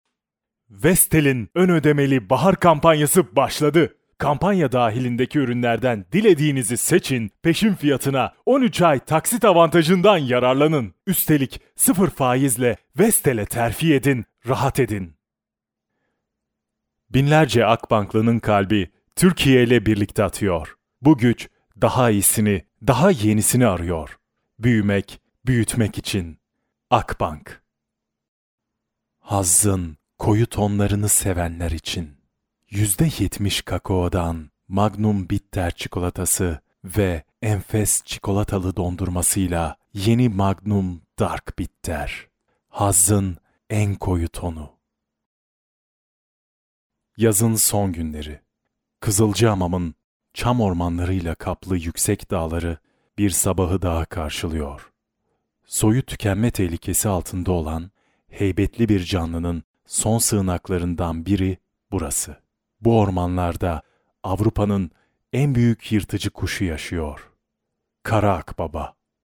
TR BE EL 01 eLearning/Training Male Turkish